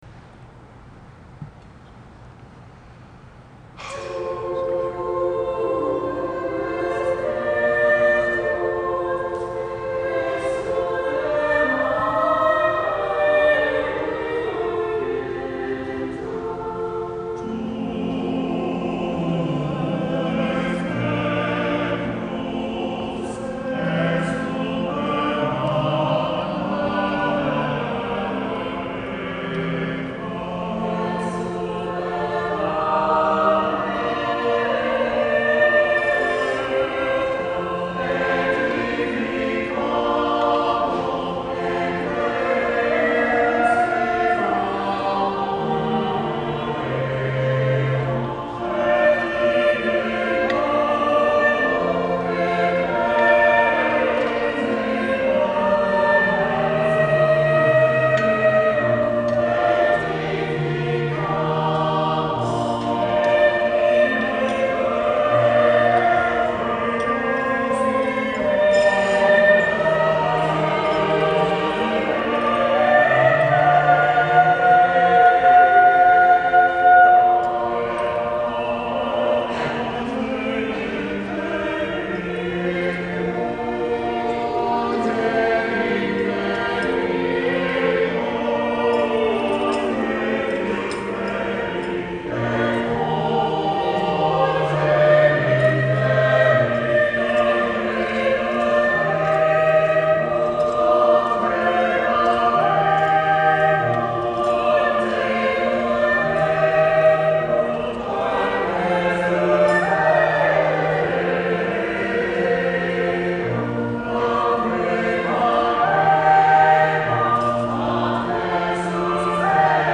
Tu es Petrus Palestrina (Adult Choir) - St. Mary's Catholic Church
Tu es Petrus Palestrina (Adult Choir)